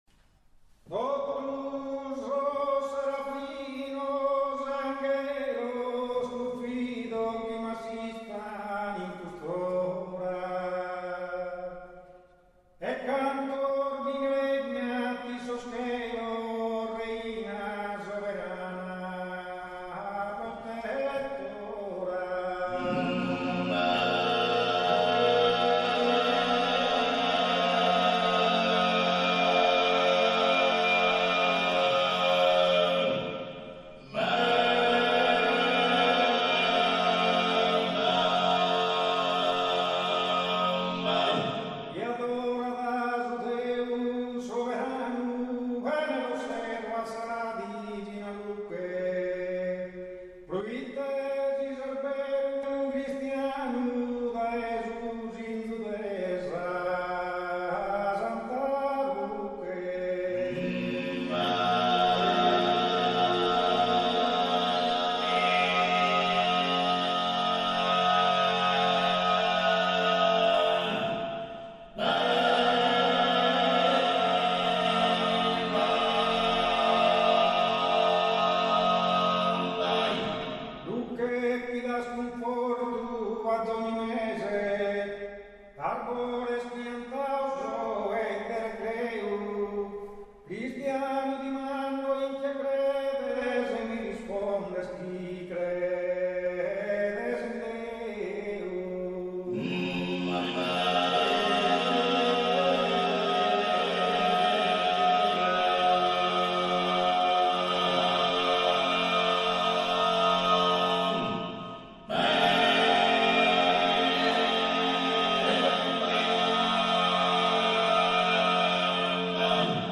Pregadoria - Chiesa San Gavino - Gavoi
Pregadoria Cantu a sa seria